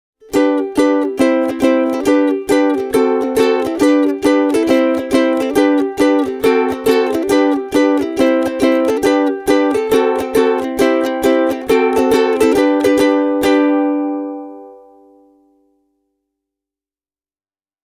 Ja molemmista äänilähteistä yhdistetty signaali on tällainen: